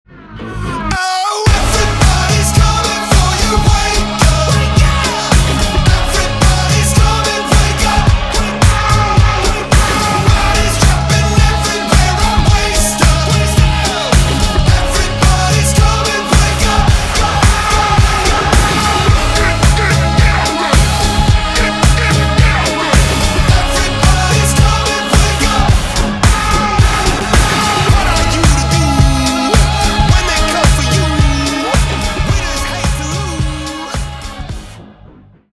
зарубежные громкие